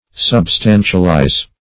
Search Result for " substantialize" : The Collaborative International Dictionary of English v.0.48: Substantialize \Sub*stan"tial*ize\, v. t. [imp.